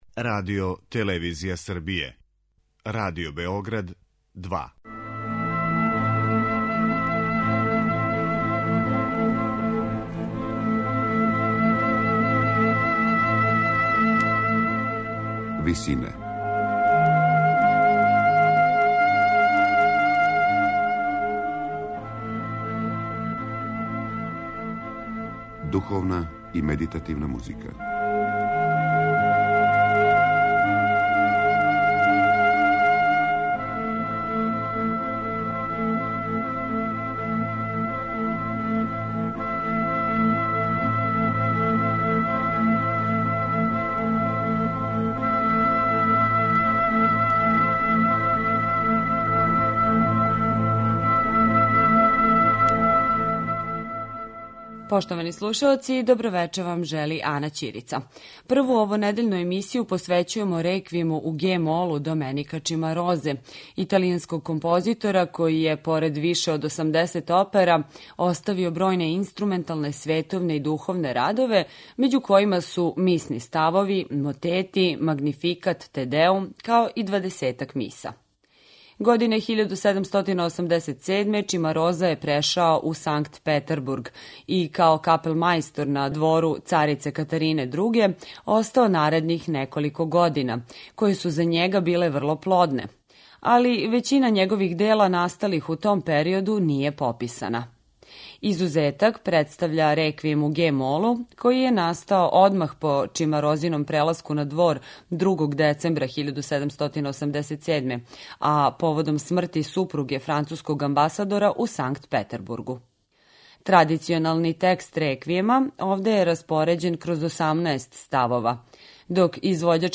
Емисија духовне и медитативне музике